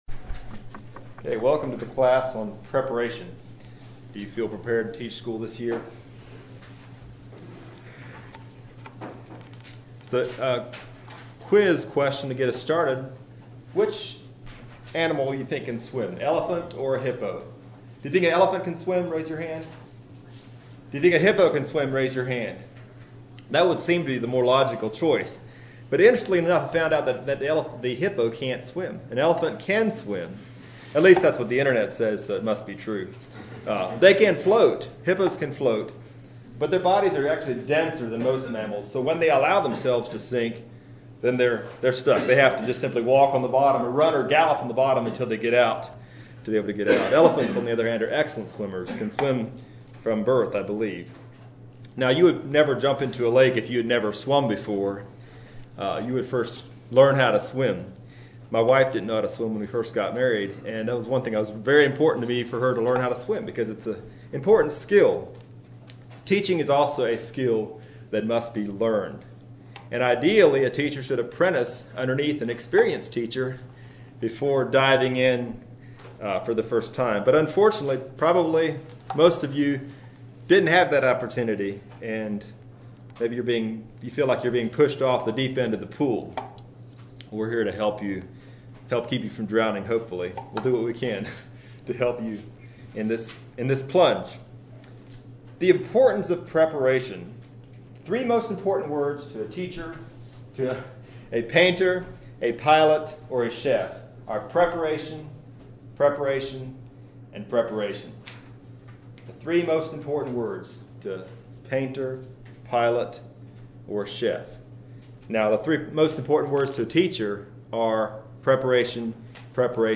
If you feel like an elephant that has been dropped into the ocean, this class will give you some techniques and strategies to help keep your head above water! This session is intended for new teachers.